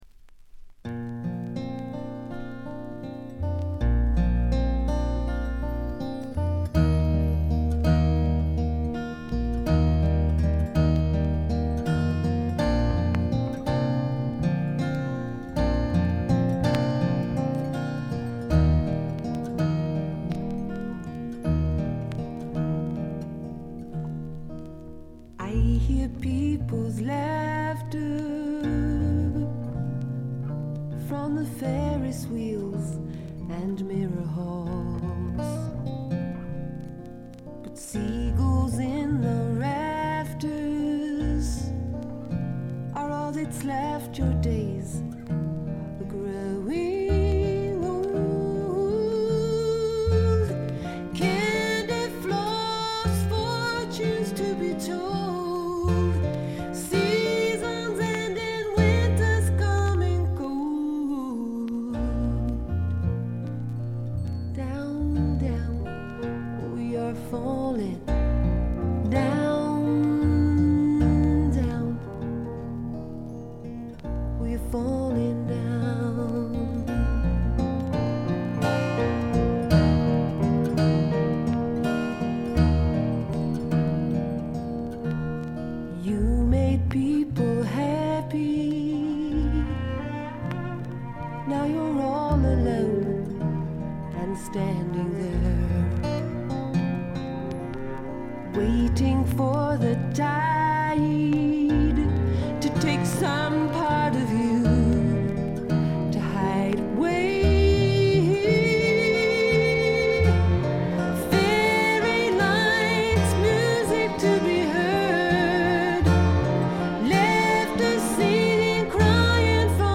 バックグラウンドノイズが常時出ており静音部ではやや目立ちます。B2序盤でプツ音2-3回。
英国の女性シンガー・ソングライター／フォークシンガー。
試聴曲は現品からの取り込み音源です。